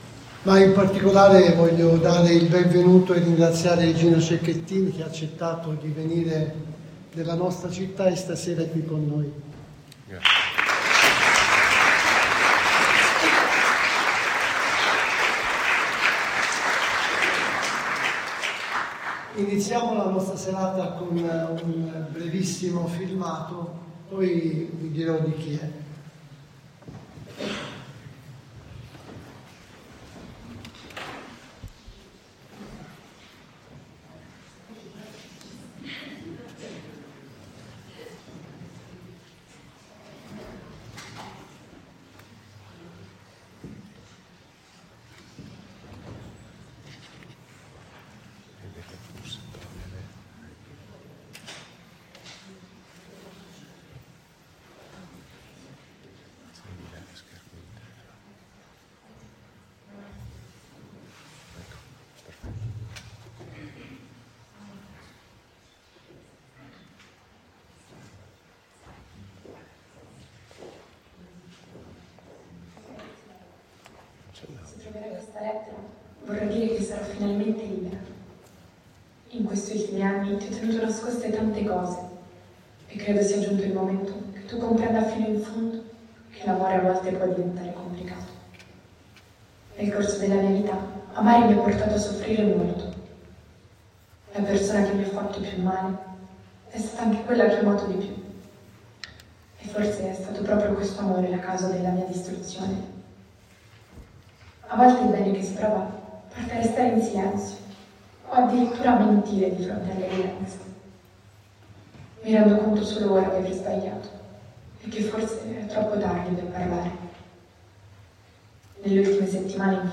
Ma di come danzare nella pioggia...Incontro con Gino Cecchettin, il padre di Giulia.